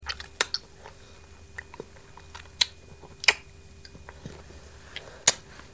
babyeat.wav